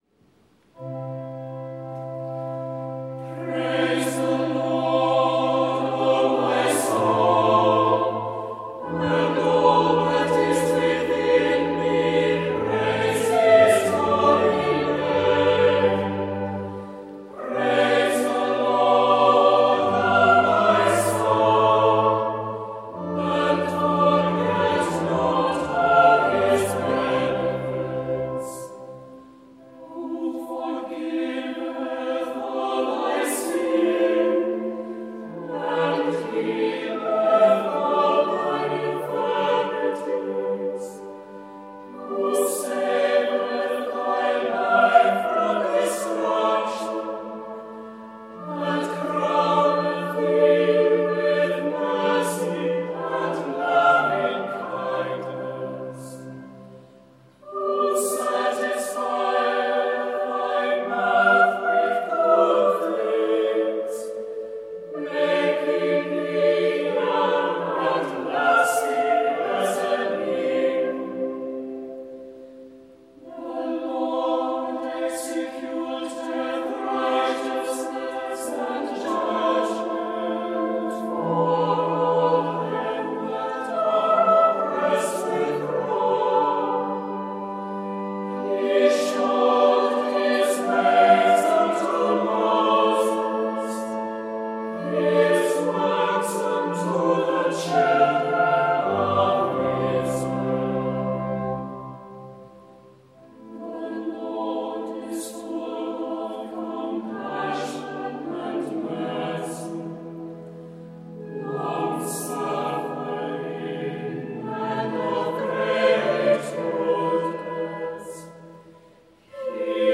Psalm 103, sung by the Priory Singers of Belfast at Truro Cathedral